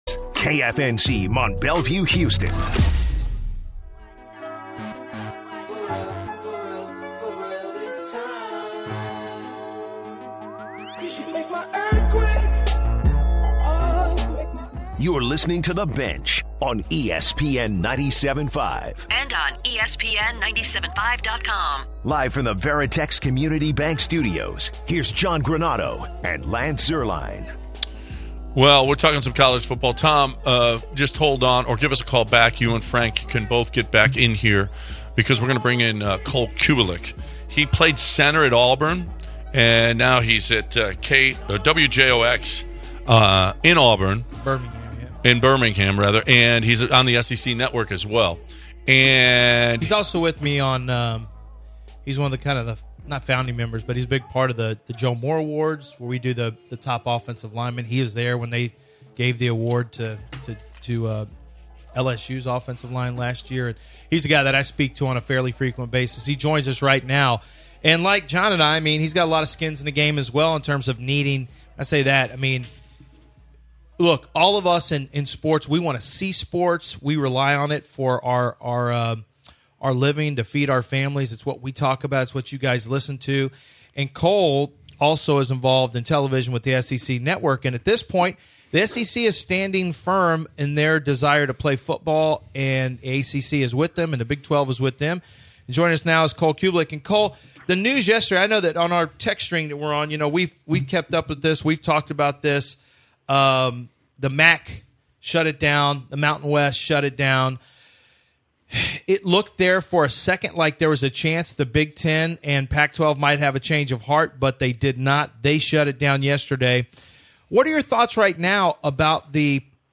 Discuss the decisions of these conferences and take calls from listeners.